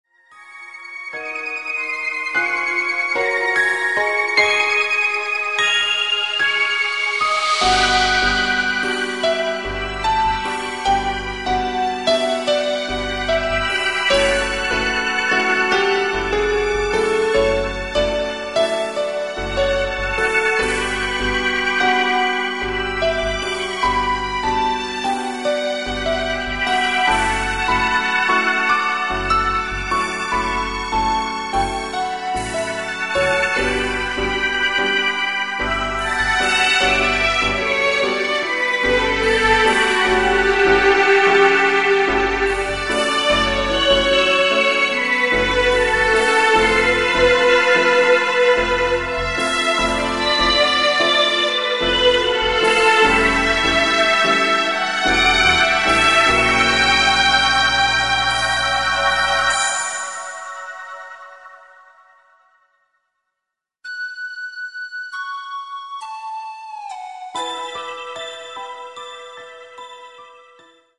この後、曲のイメージに合わせてパソコンで他の楽器を加え、曲に色取りをつけたりします。オリジナル色の強い編曲では例えばこんな感じです。